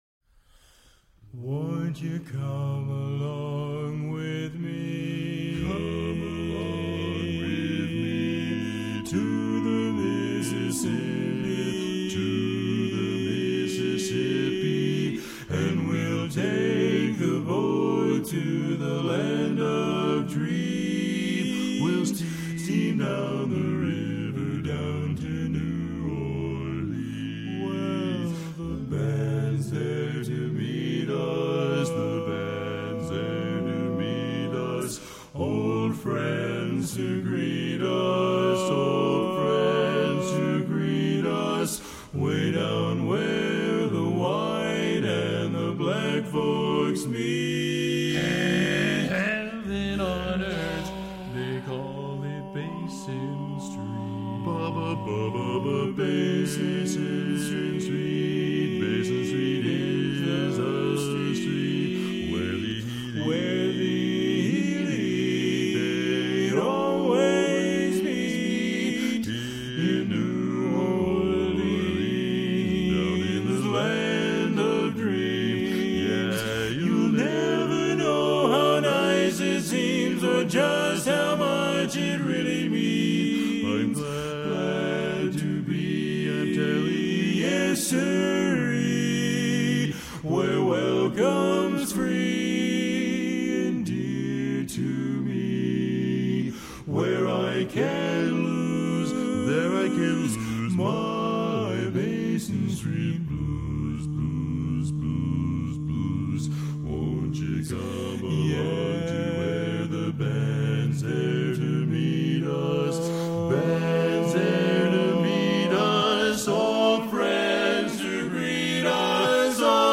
a quartet and sound of distinction!